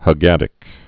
(hə-gădĭk, -gädĭk, -gôdĭk)